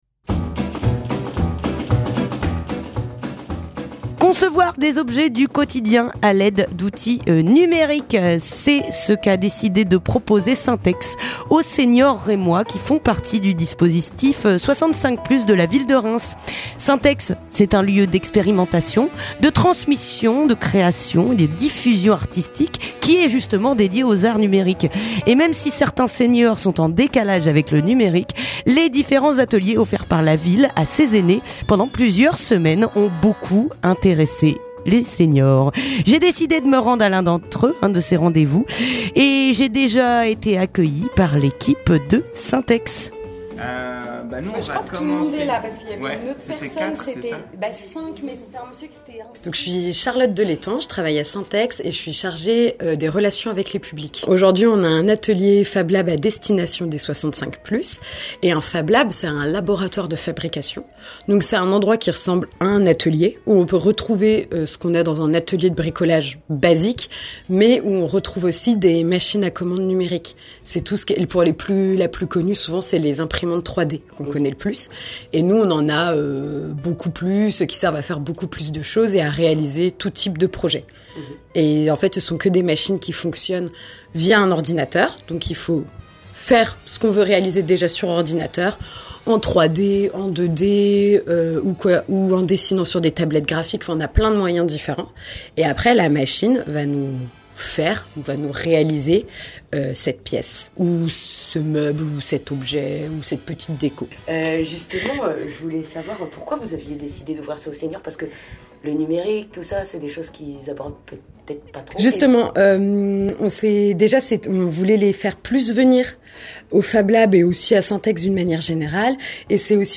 Reportage à Saint-Ex (14:00)
Radio Primitive s'est rendu à une de ces séances pour avoir une idée du déroulement d'un atelier de conception et récolter la parole des séniors présents·es.